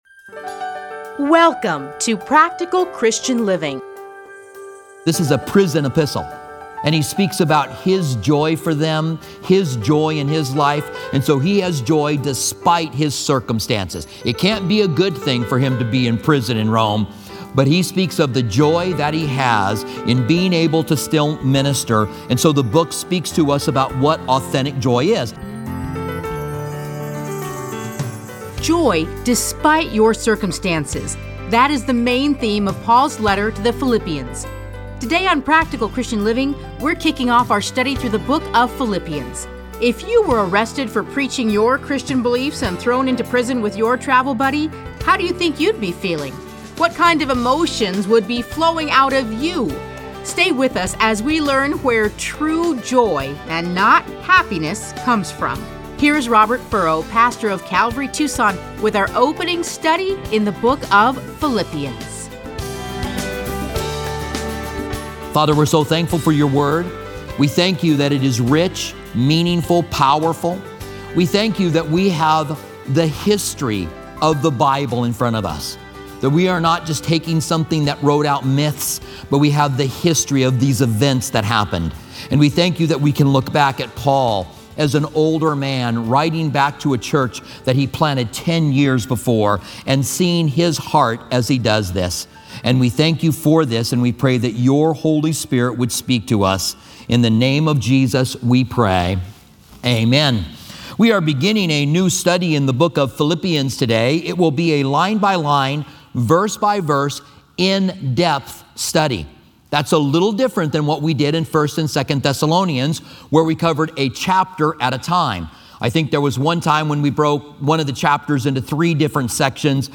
Listen to a teaching from A Study in Philippians 1:1-30.